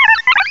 cry_not_happiny.aif